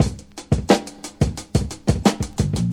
• 117 Bpm Breakbeat C Key.wav
Free drum beat - kick tuned to the C note. Loudest frequency: 1130Hz
117-bpm-breakbeat-c-key-cOF.wav